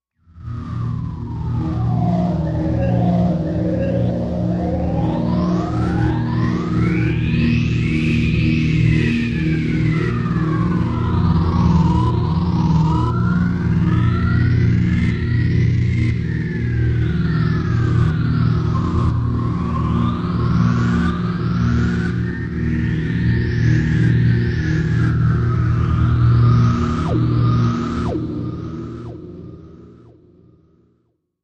Dark Wind Mystic Deep Cave Wind Rising Swirling